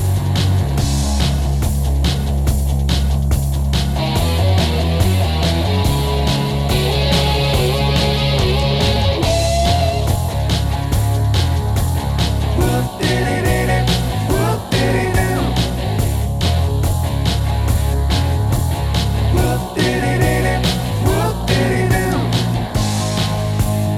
One Semitone Down Rock 4:11 Buy £1.50